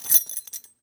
foley_keys_belt_metal_jingle_07.wav